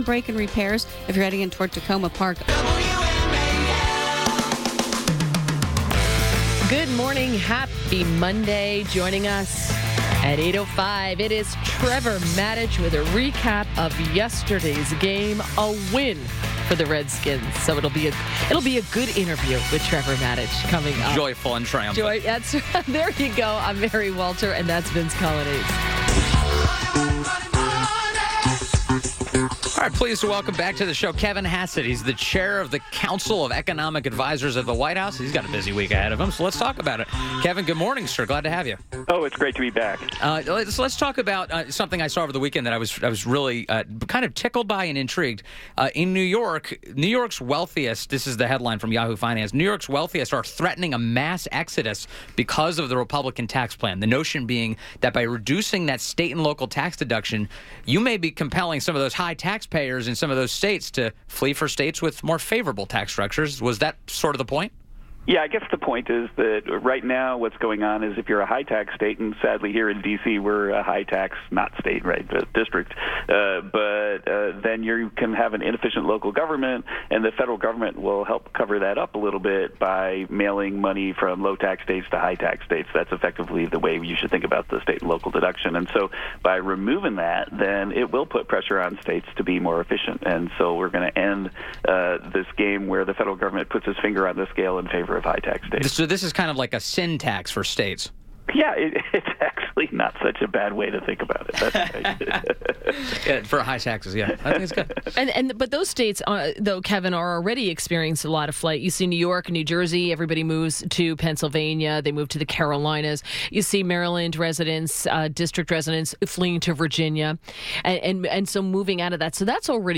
INTERVIEW – KEVIN HASSETT – Chair of the Council of Economic Advisers at The White House – previewed the tax vote on Tuesday.